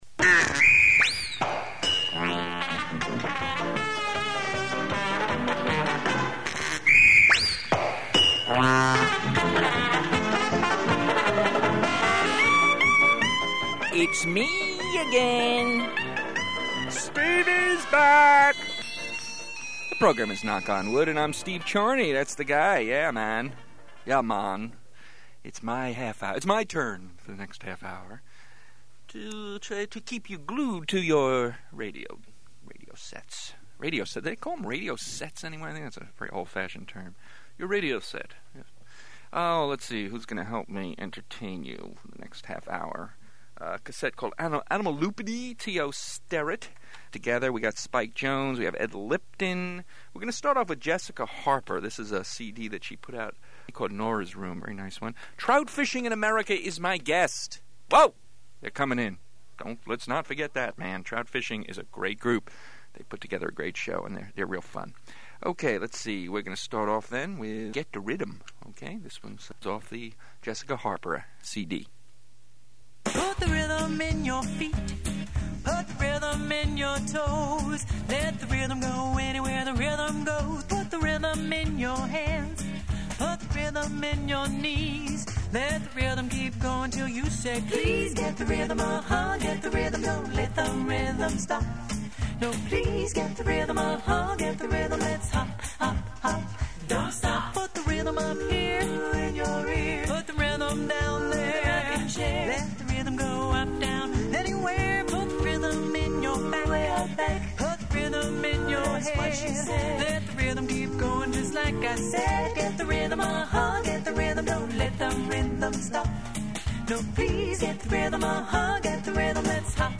Trout Fishing in America are guests on the program.
Knock On Wood Comedy Show